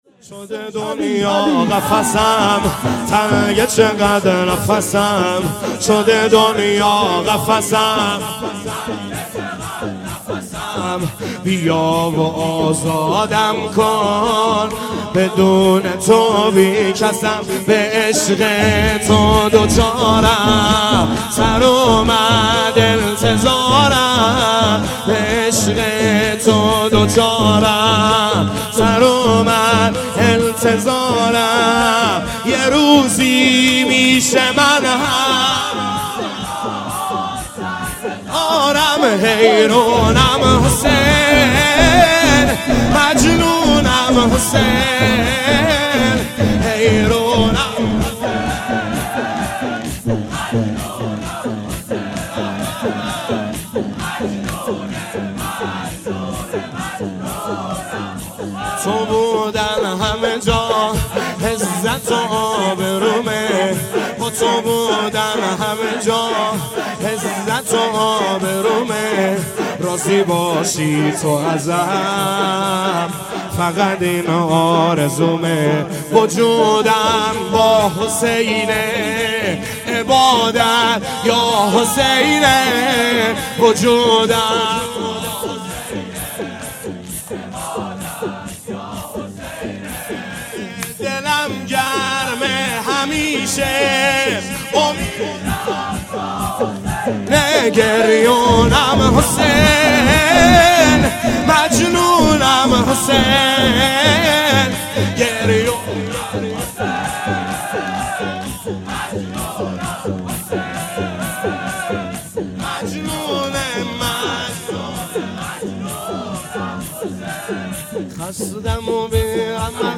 شور | به عشق تو دچارم
گزارش صوتی شب هشتم محرم 97 | هیأت محبان حضرت زهرا سلام الله علیها زاهدان